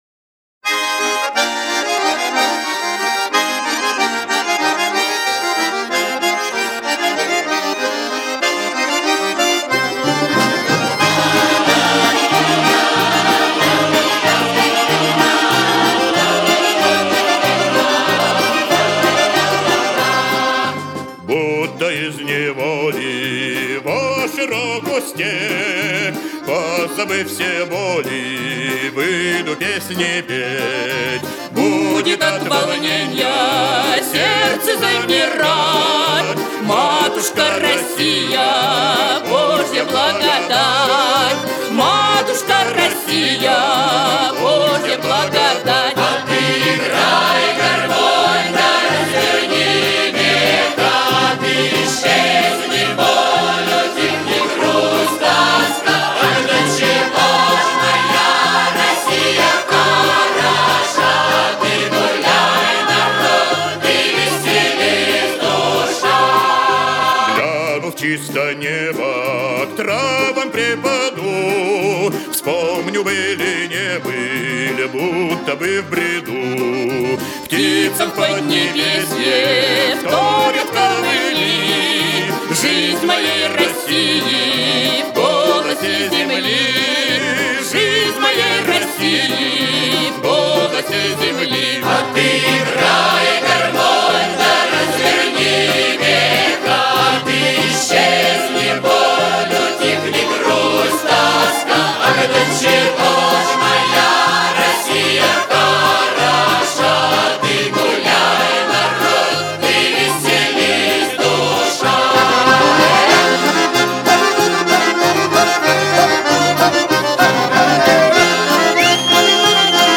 • Жанр: Детские песни
теги: Россия, день России, минус, народный мотив